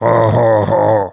frank_vo_02.wav